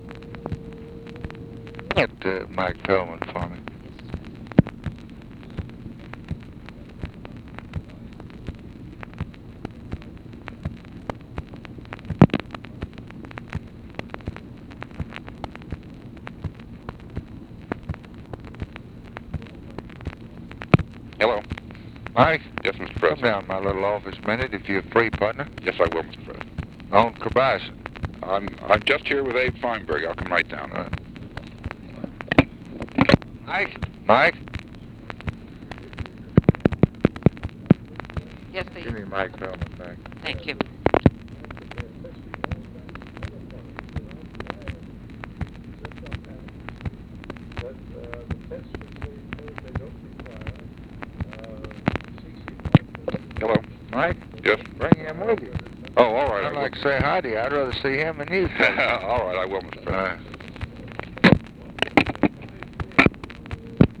Conversation with MYER FELDMAN, March 12, 1964
Secret White House Tapes | Lyndon B. Johnson Presidency